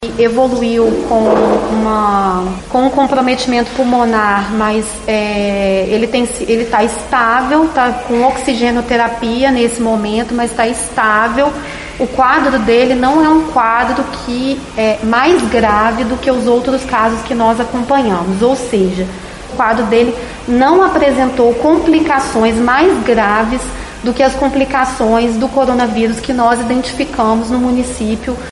Em coletiva de imprensa na tarde desta sexta-feira, 28, O caso da pessoa internada, em Juiz de Fora, com a cepa indiana do novo coronavírus foi explicado pela Secretaria Municipal de Saúde e pela Superintendência Regional de Saúde de Juiz de Fora (SRS-JF).
estavel_Coletiva-cepa-indiana-JF_Sec-Saude-Ana-Pimentel-2.mp3